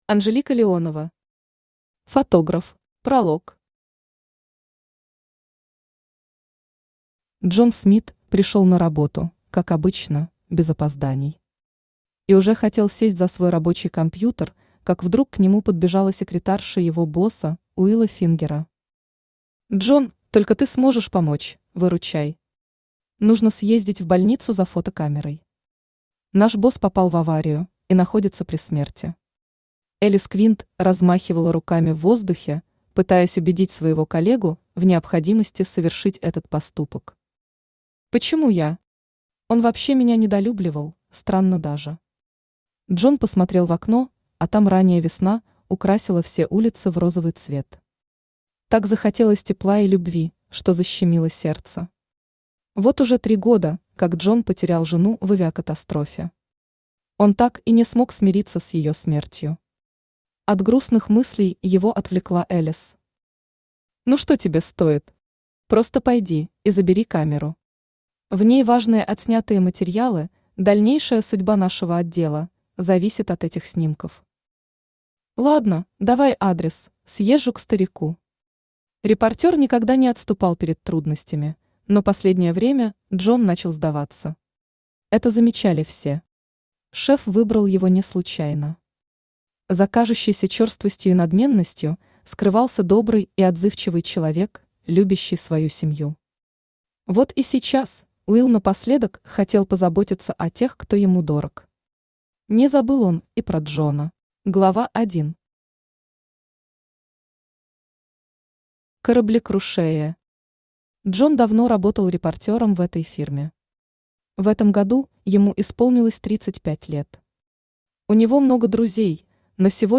Аудиокнига Фотограф | Библиотека аудиокниг
Aудиокнига Фотограф Автор Анжелика Александровна Леонова Читает аудиокнигу Авточтец ЛитРес.